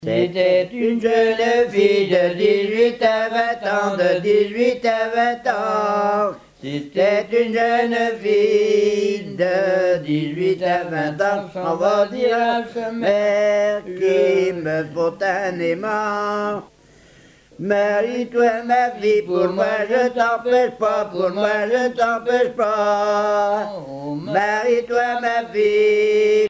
Chansons traditionnelles
Pièce musicale éditée